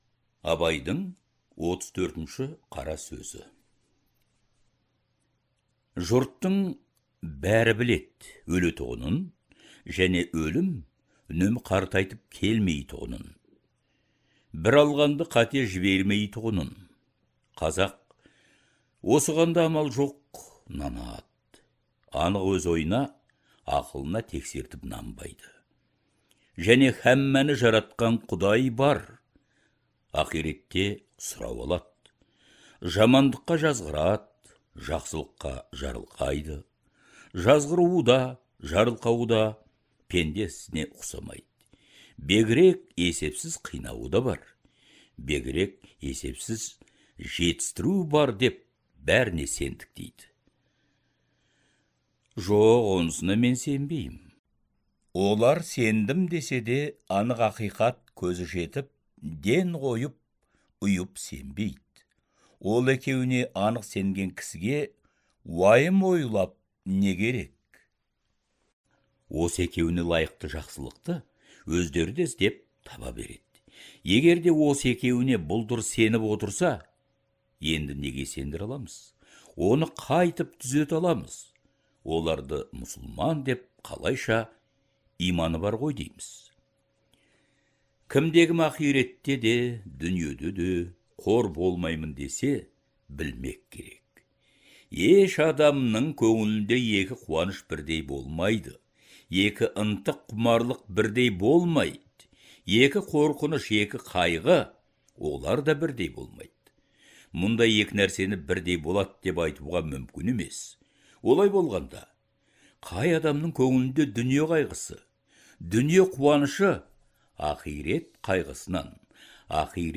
Даналық көсемсөздердің аудио нұсқасы Әдебиет институтының студиясында жазылып алынған.